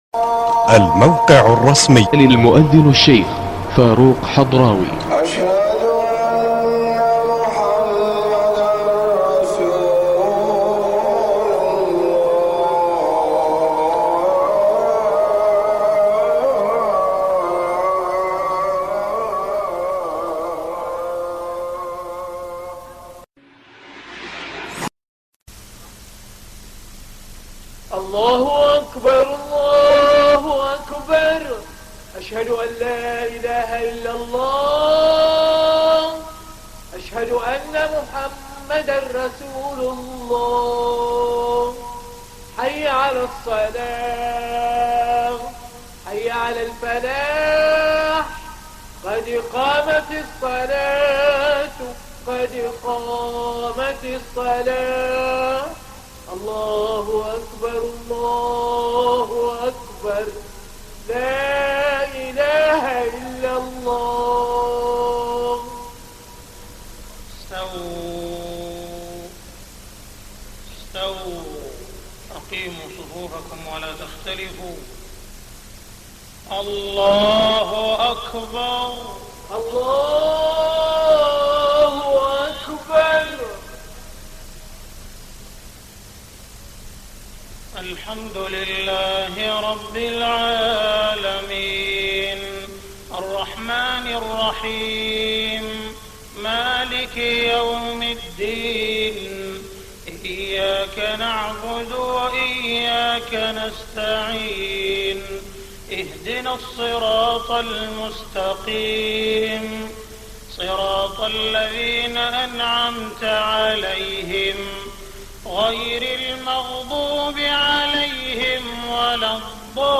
صلاة المغرب 17 رمضان 1423هـ سورتي الطارق و الكوثر > 1423 🕋 > الفروض - تلاوات الحرمين